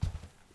krok_02.wav